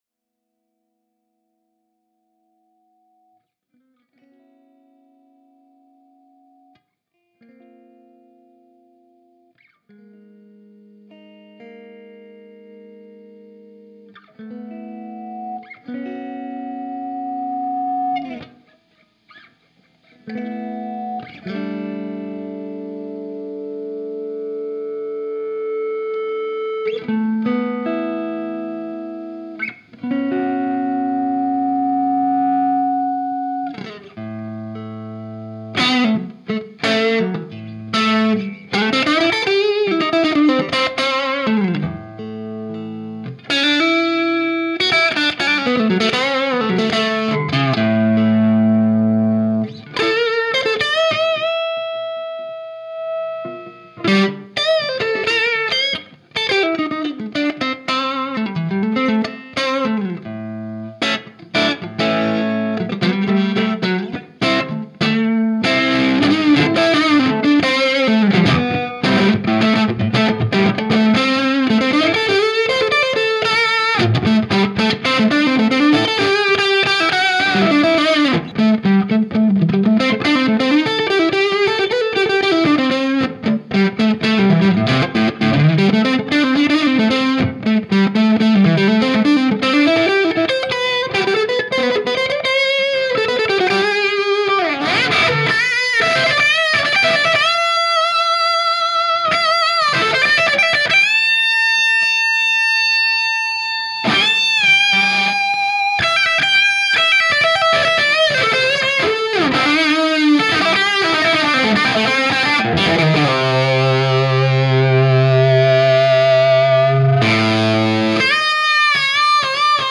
So, here's a wacky Express clip, no movie necessary!
Sounds like they still have some life left in them to me, Great playing, not a bad recording either.
Yeah, it was a Warmoth hardtail strat alder/rosewood, Van Zandt pickups, and an early seventies Marshall 1960B with later 1977 75hz G12M Blackbacks. SM57 right into the Zoom Recorder. I put a little reverb on with my "vintage" copy of Sound Forge.
I think it adds a touch more swirl, but it also puts a little touch of grainy fizz on top.
A good demonstration of the versatility of tones from a single-channel amp :)
I love the feedback on the cooler intro and outtro bits.
Sounds close mic'd to me.